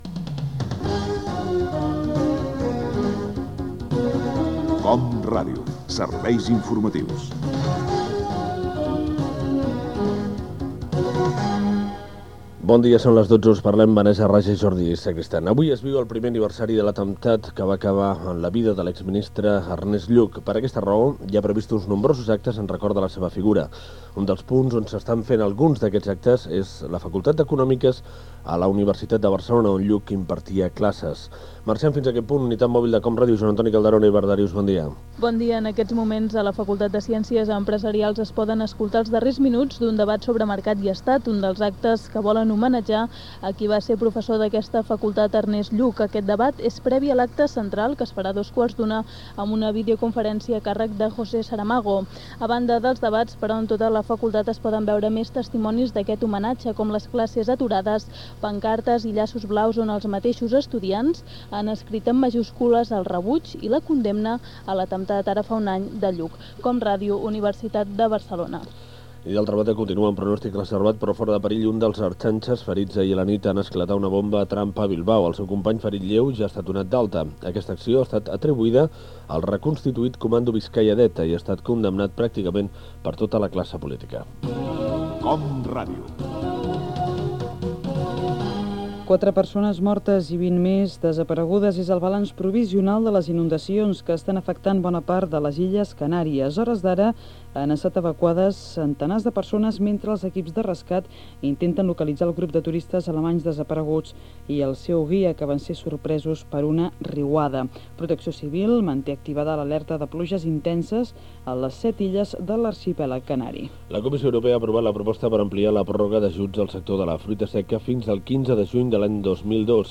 Butlletí informatiu 12.00 del migdia. Primer aniversari de l'assassinat del polític Ernest Lluch. Inundacions a les Illes Canàries.
Informatiu